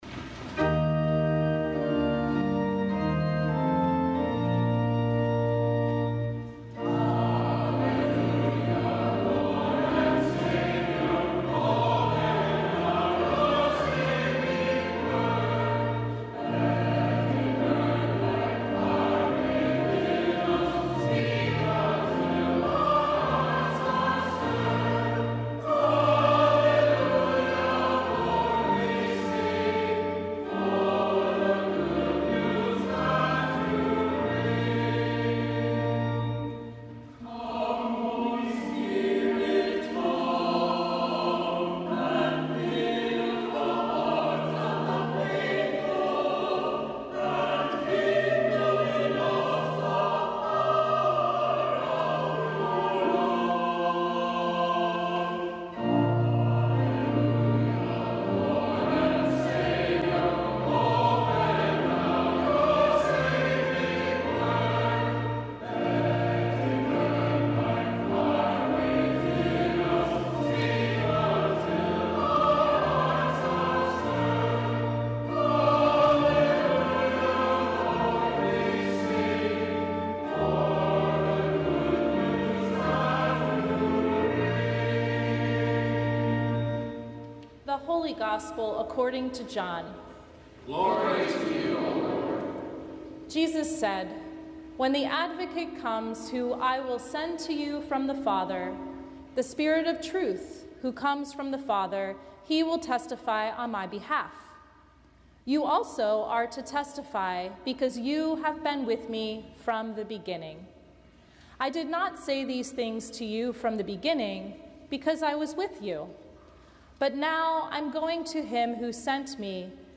Sermon from Pentecost 2024